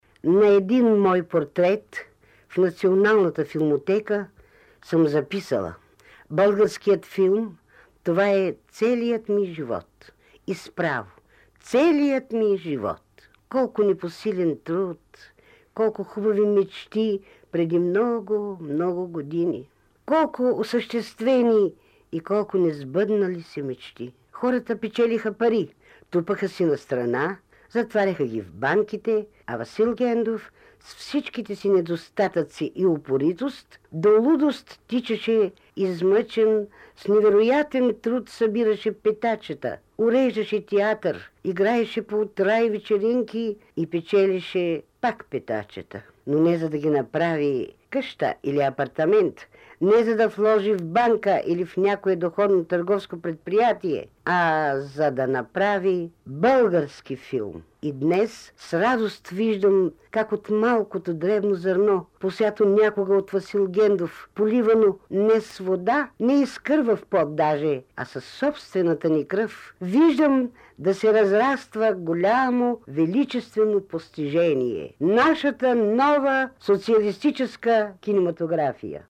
Жана Гендова говори за своите сбъднати и несбъднати мечти, запис 1970 година, Златен фонд на БНР: